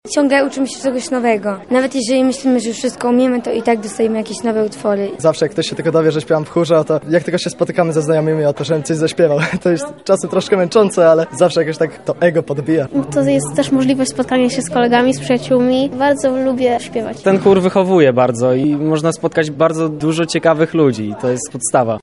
Lubelskie Słowiki – chórzyści
Lubelskie-Słowiki-chórzyści.mp3